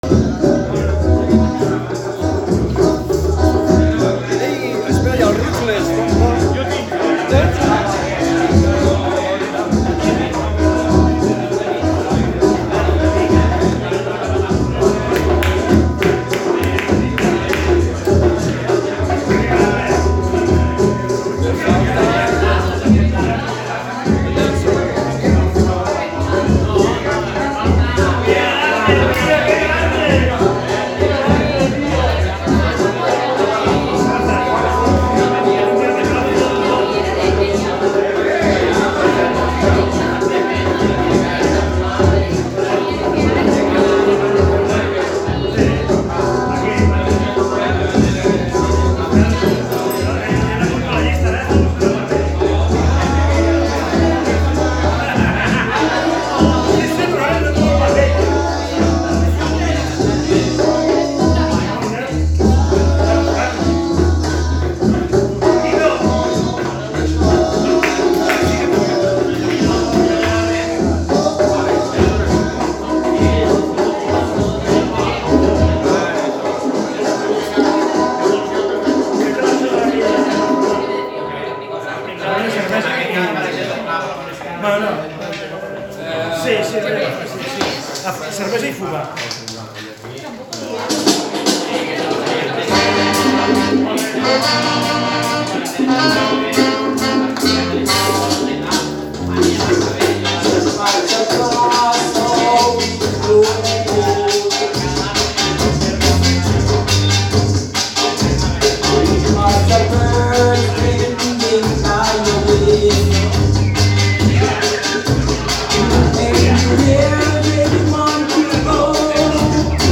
Warm up / Shut down Dub Jam Session
At "Ass. Cult. LOS TRECERS" BARCELONA